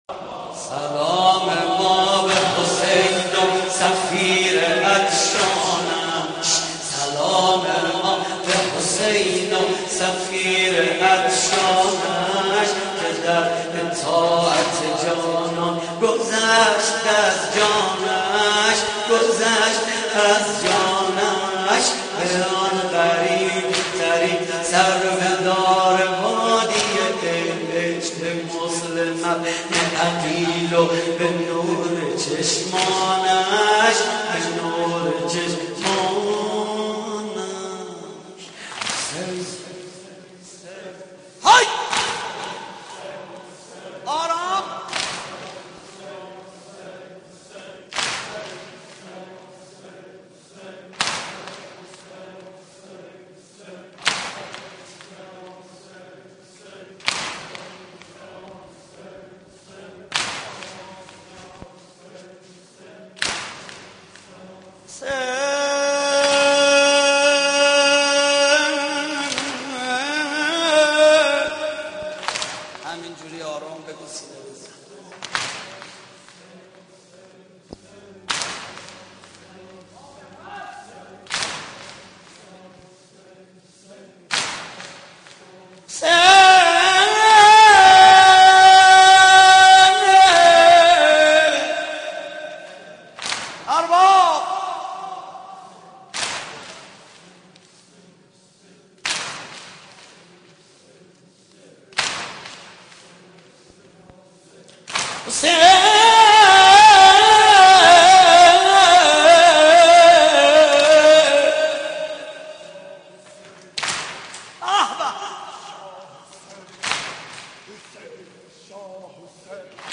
محرم 88 - سینه زنی 7
محرم-88---سینه-زنی-7